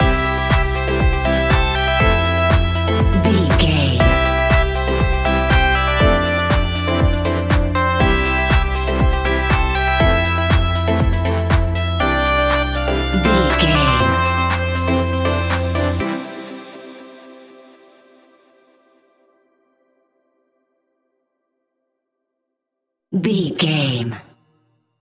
Ionian/Major
groovy
dreamy
smooth
drum machine
synthesiser
funky house
deep house
nu disco
upbeat
funky guitar
wah clavinet
fender rhodes
synth bass
horns